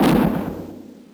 explodeRocket.ogg